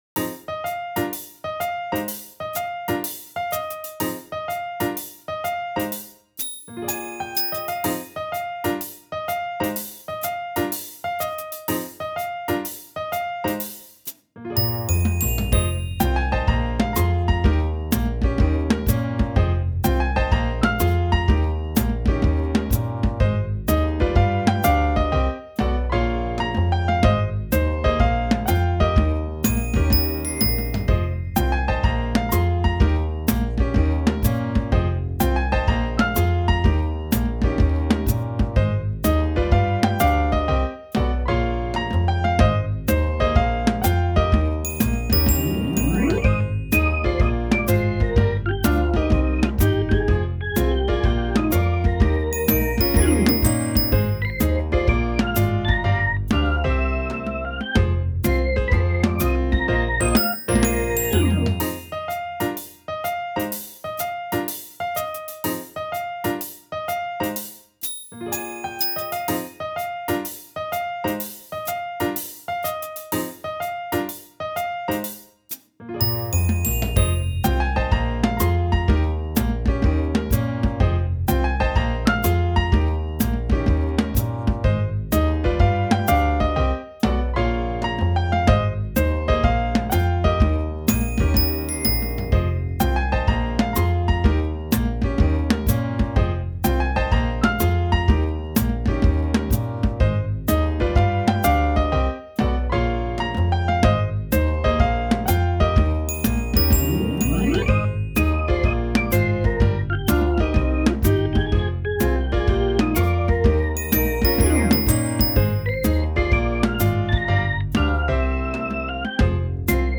大切な人との約束までの時間、わくわくして嬉しいけれど、ちょっと不安。でも心はふわふわあったかい。